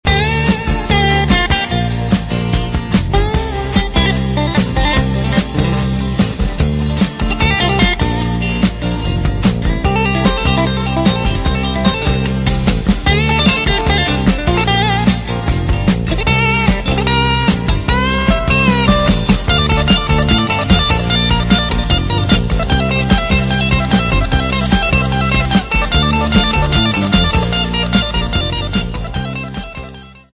Ils sont au format mp3, 32 kbps, 22 KHz, mono.